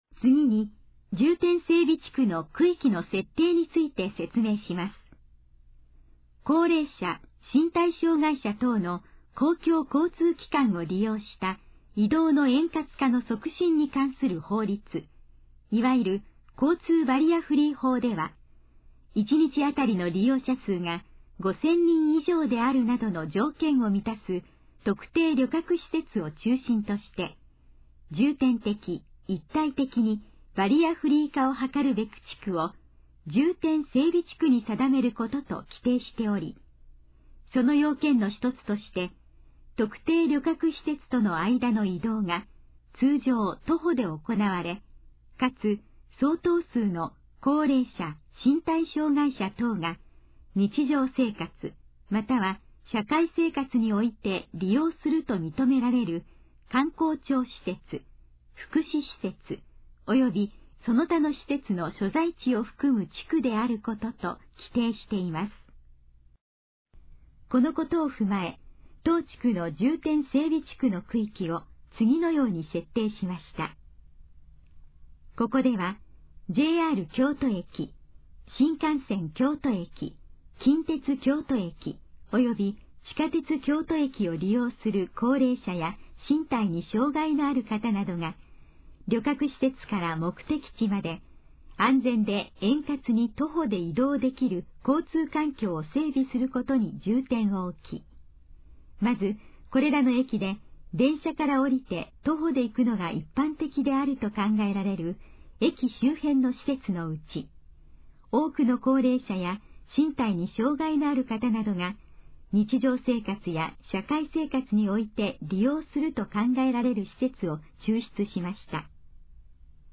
このページの要約を音声で読み上げます。
ナレーション再生 約446KB